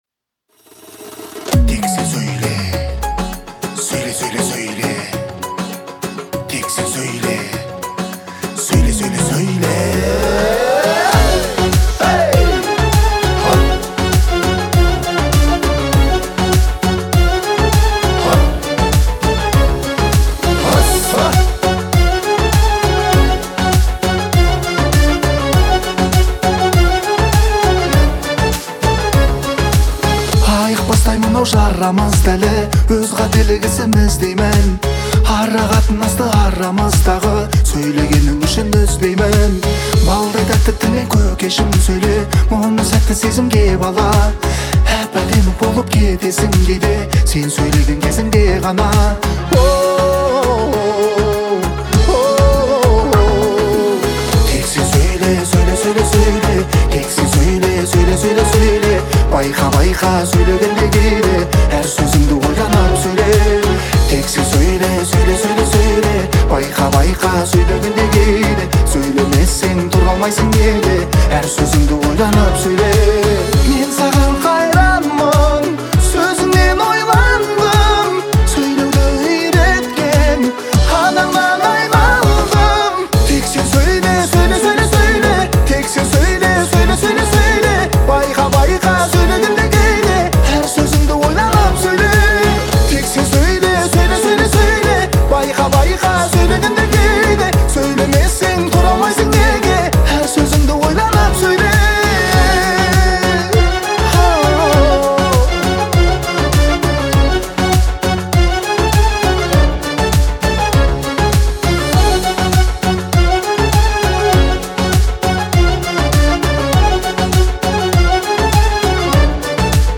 это романтический трек в жанре поп